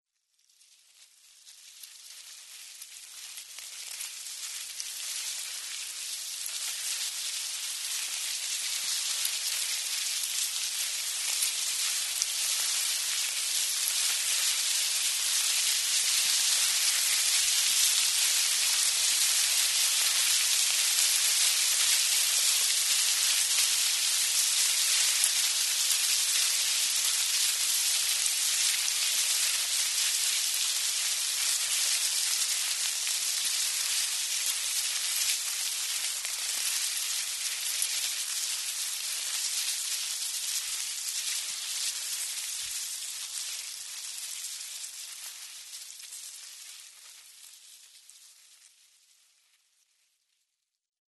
Шорох шагов муравьиной колонии на листьях